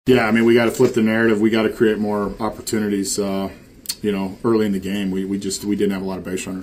Manager Derek Shelton says the Pirates are hoping the bats wake up soon.